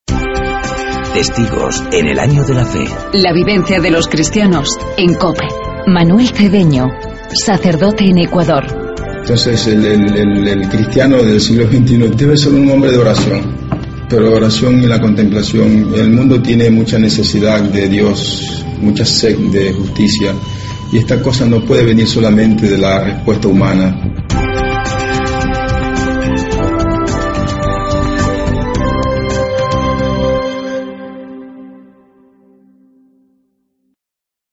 sacerdote de Ecuador.